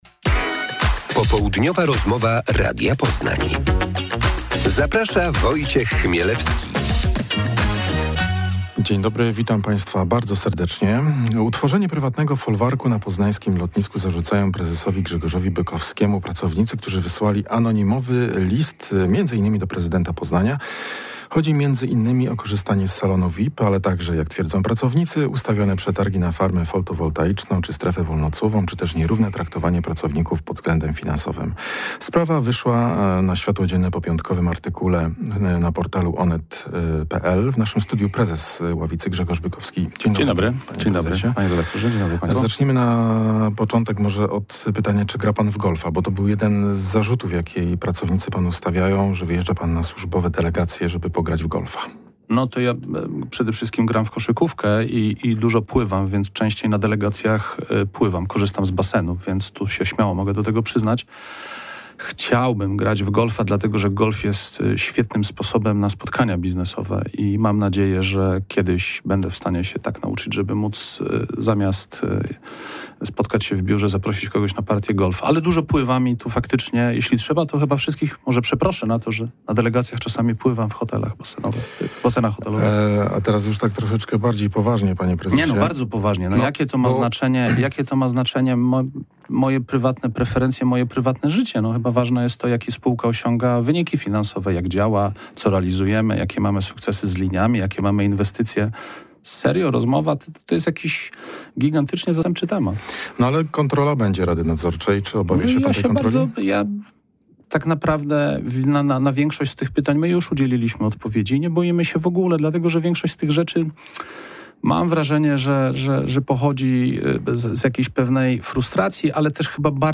Autor: , Tytuł: 23 06 popołudniowa rozmowa S